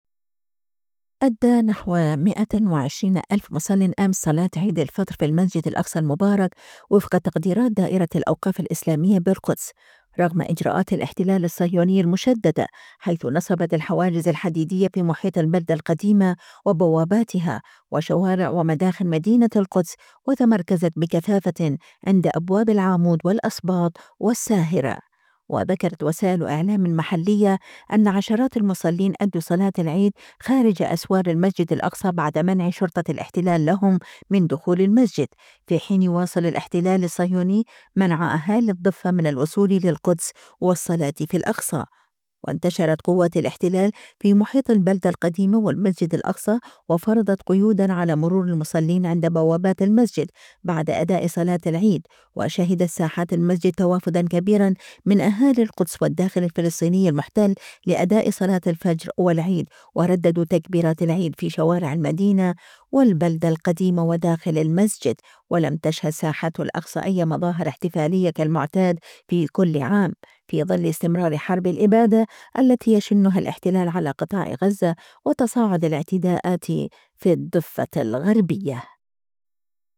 120 الف مصلٍ يؤدون صلاة عيد الفطر المبارك في المسجد الأقصى المبارك